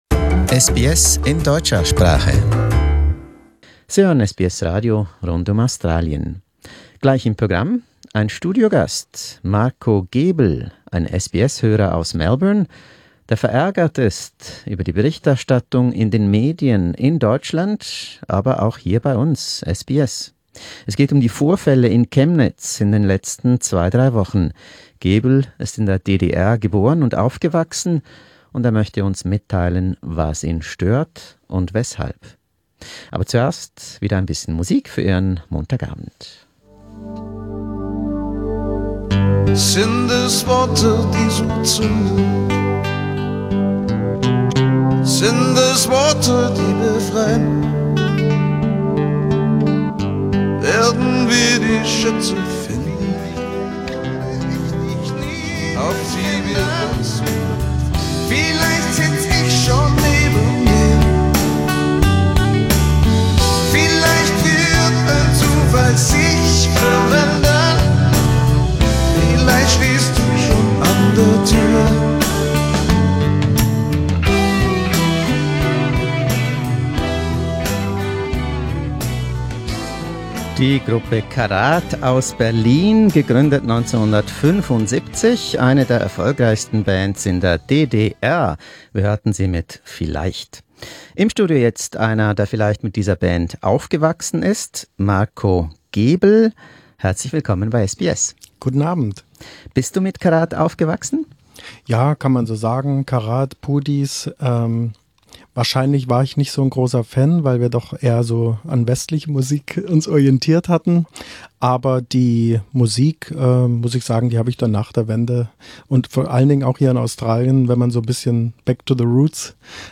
We gave him the opportunity to vent his spleen and defend his fellow East Germans in a candid live talk.